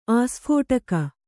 ♪ āsphōṭaka